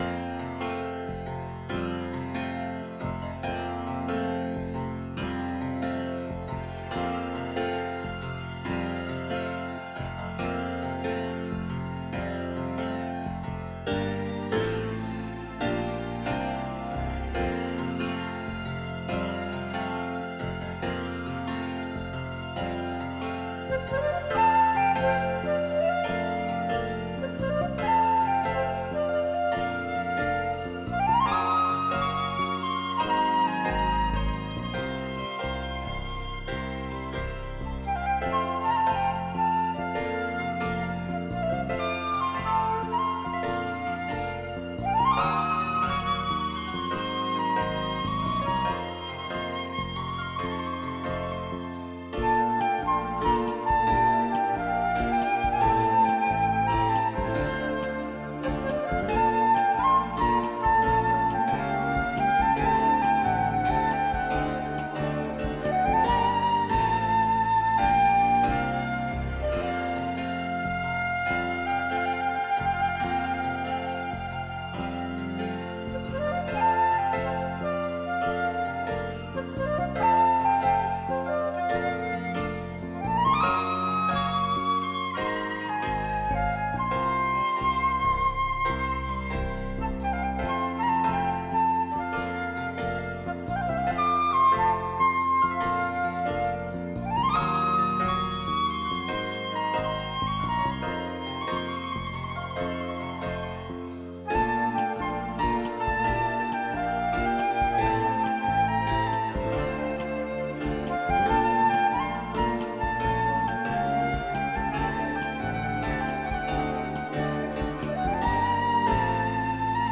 Relaxációs cd 50 perc zenével.